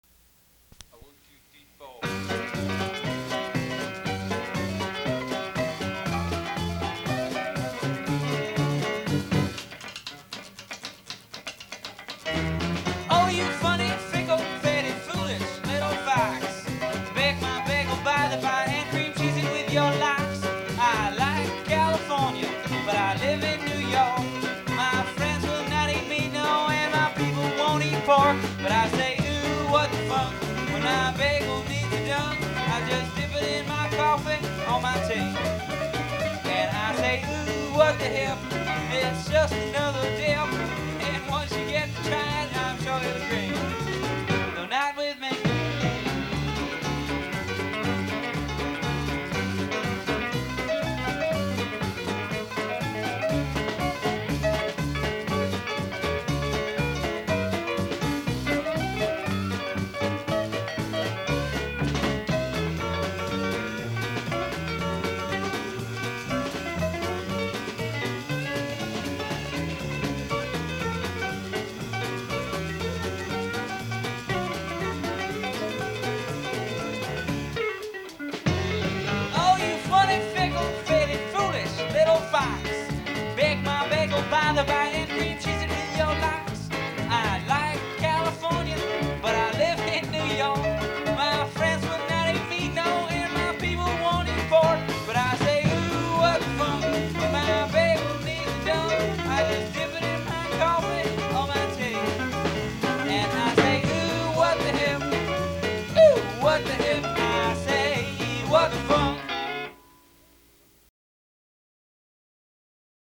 recorded at 55 West 19th St., NYC, 1974.
rhythm guitar & lead vocals
piano & vocals